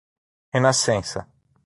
/ʁe.naˈsẽ.sɐ/